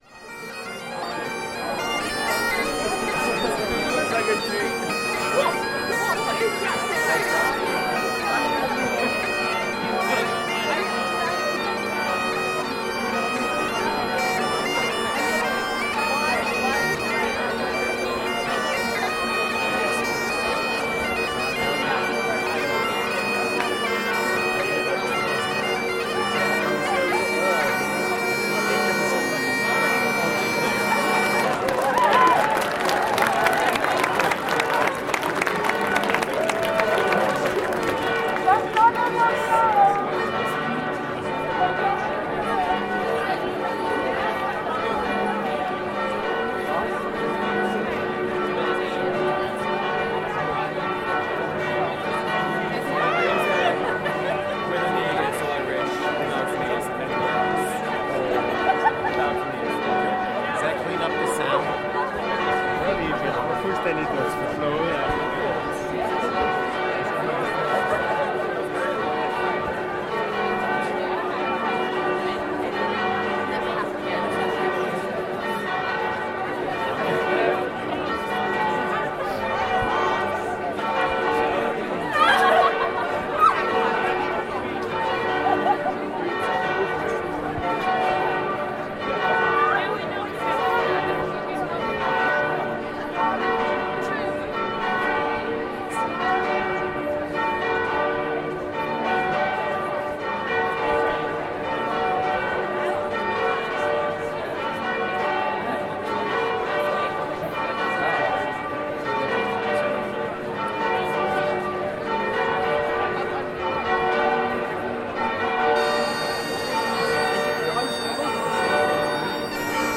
Bagpipes and St. Mary's bells
May Morning 2015, Oxford - bagpipe players, celebrating dancers and the early morning bells from St. Mary's church, outside the Radcliffe Camera.